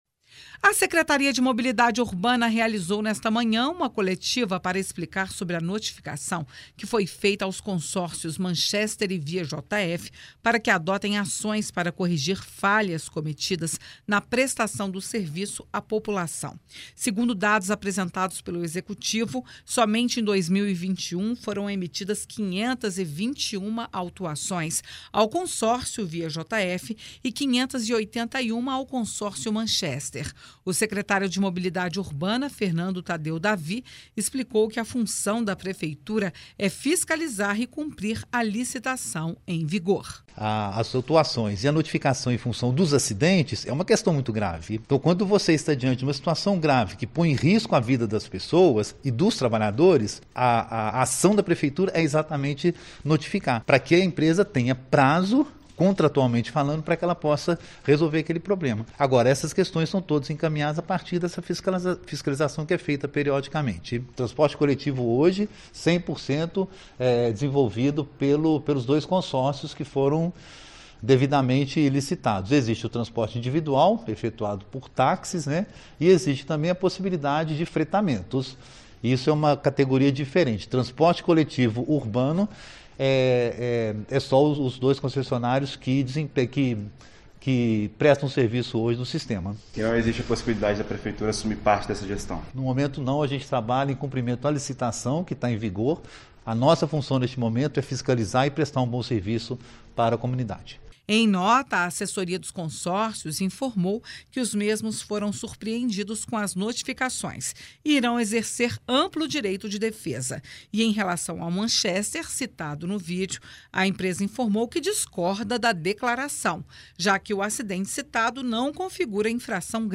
No fim da manhã desta sexta-feira, a Secretaria de Mobilidade Urbana convocou uma coletiva sobre o assunto.
21.01_Coletiva-fiscalizacao-consorcios-onibus-JF.mp3